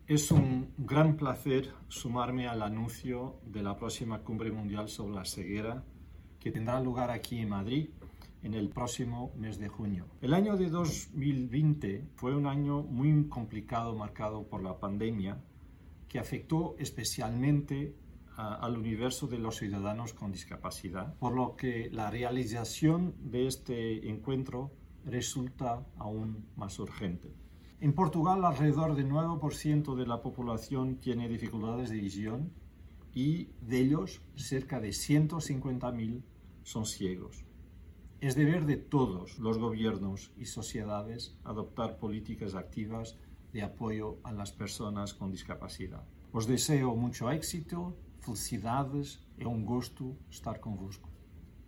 También João Mira-Gomes, embajador de Portugal en España,